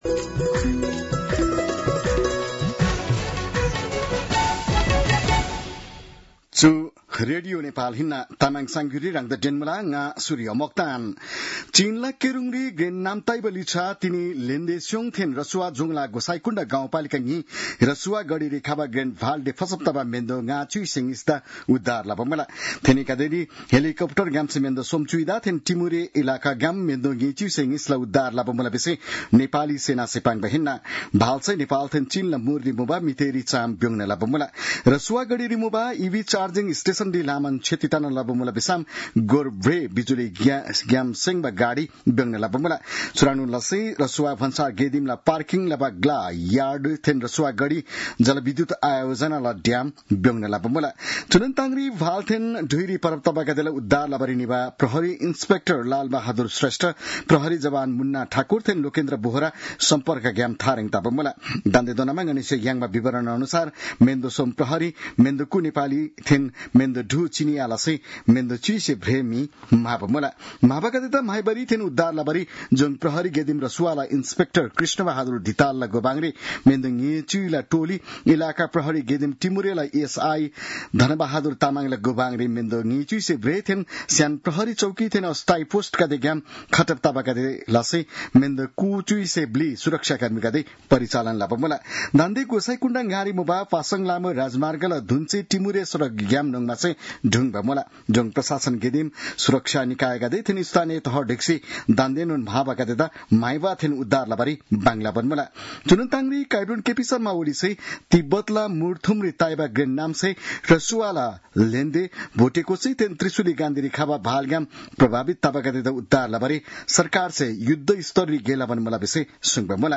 तामाङ भाषाको समाचार : २४ असार , २०८२